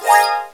Index of /m8-backup/M8/Samples/Fairlight CMI/IIX/PLUCKED
HARPARP.WAV